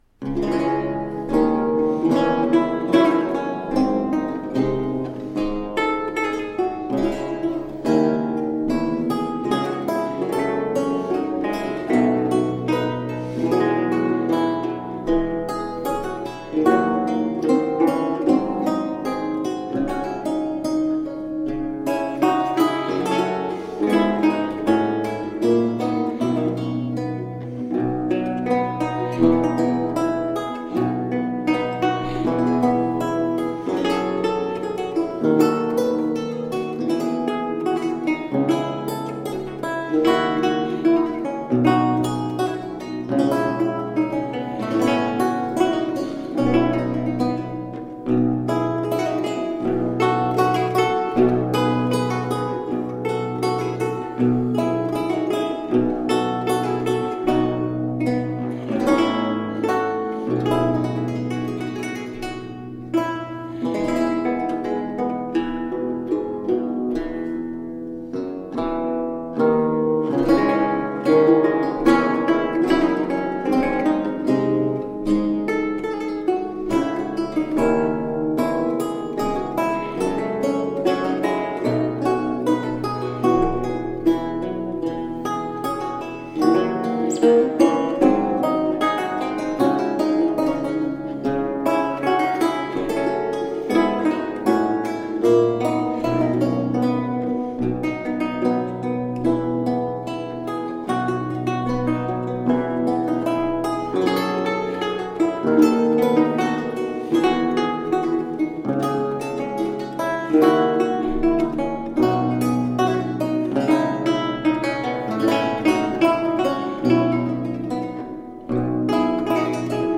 A feast of baroque lute.
Tagged as: Classical, Baroque, Instrumental, Lute